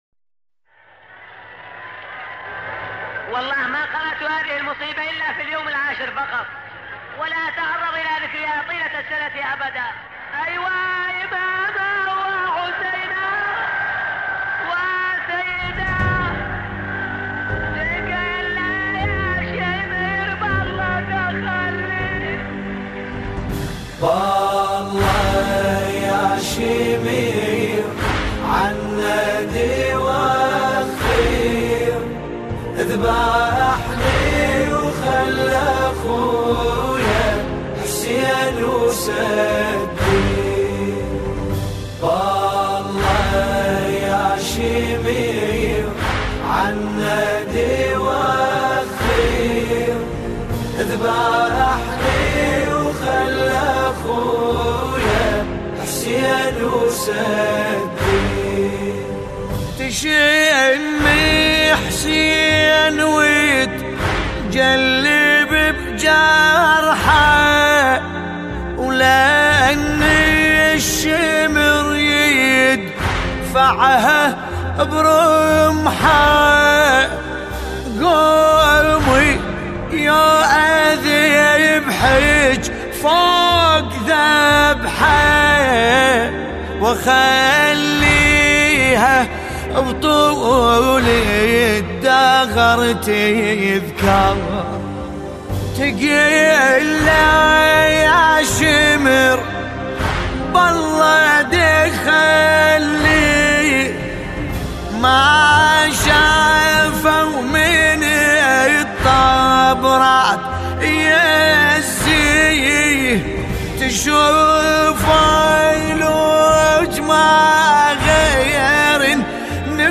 المقتل ، باسم الكربلائي